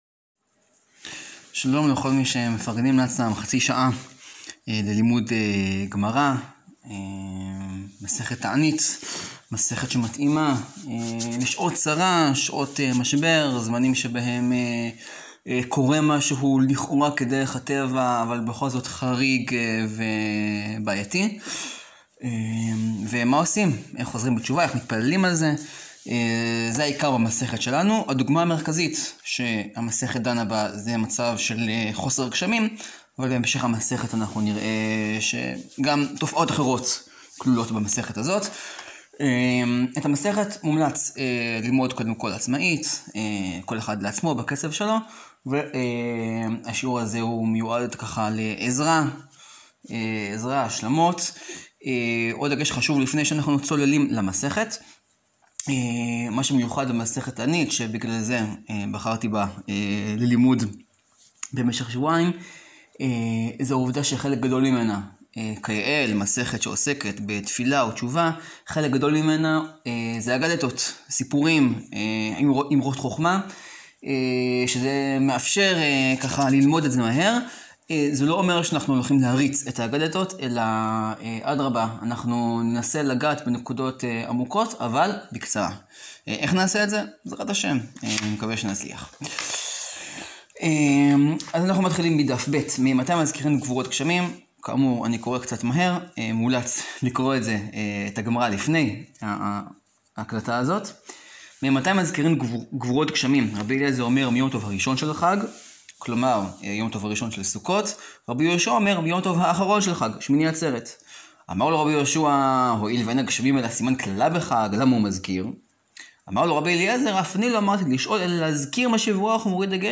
שיעור 1 להאזנה: מסכת תענית, דפים ב-ג.